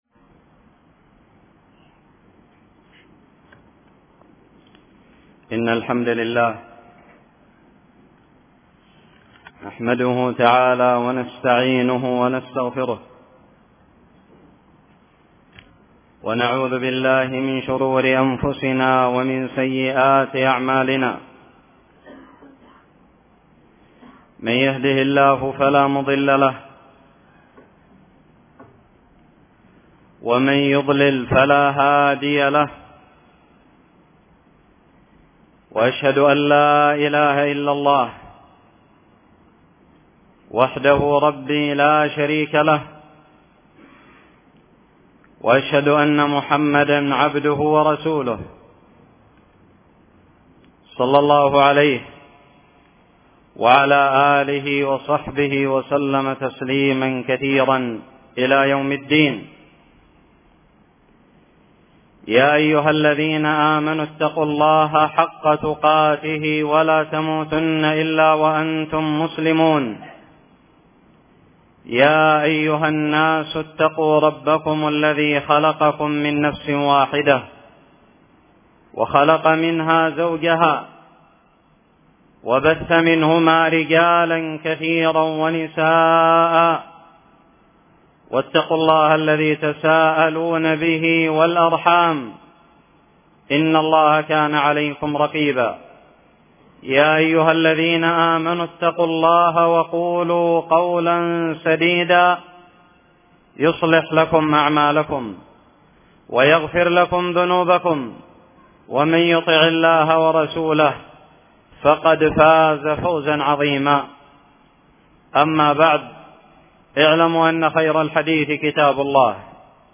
خطب الجمعة
ألقيت بدار الحديث السلفية للعلوم الشرعية بالضالع في 6 شوال 1438هــ